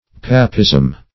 Papism \Pa"pism\, n. [F. papisme.